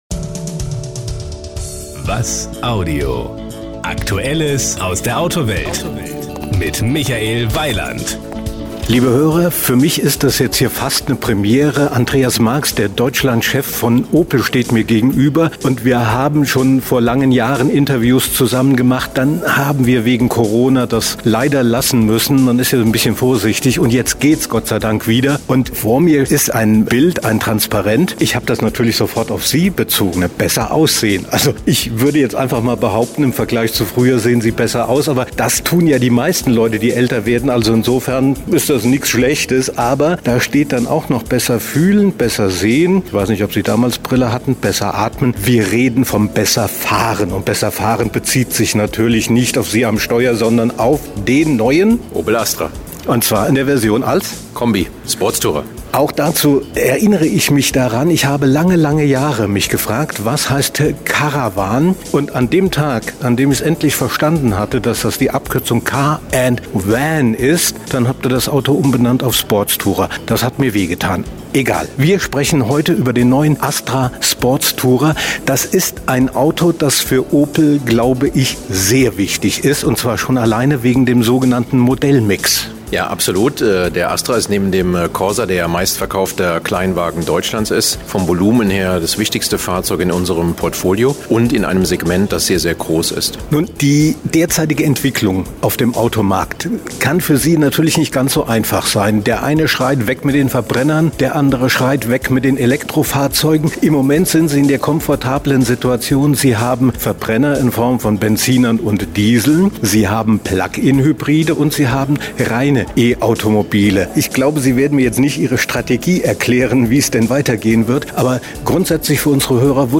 Sie sind hier: Start » Interviews » Interviews 2022